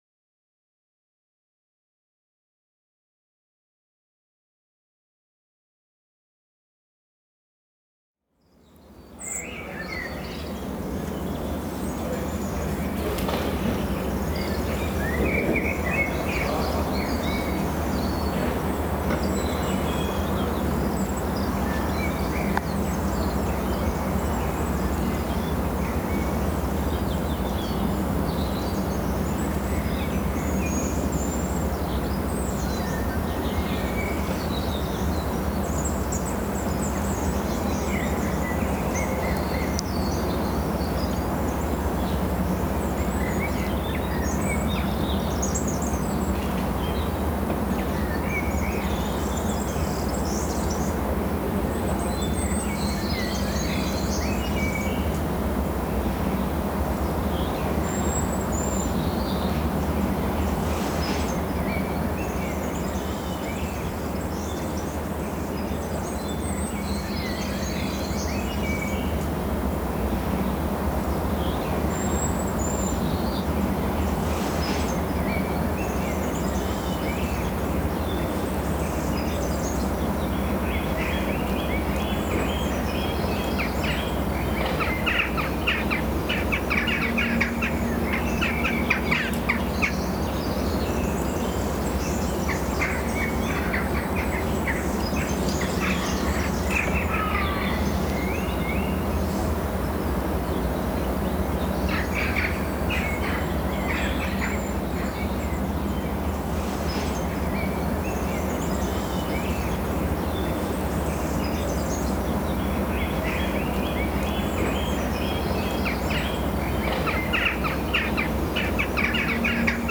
AMB_Intro_Ambience_L.ogg